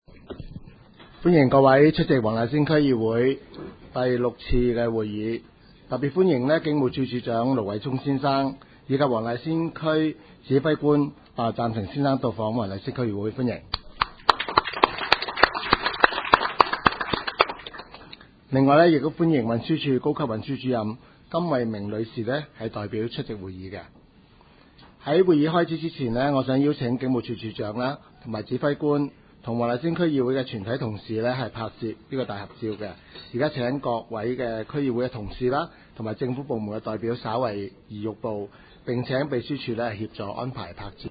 区议会大会的录音记录
黄大仙区议会会议室
开会致辞